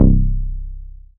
MZ 808 [Mustard].wav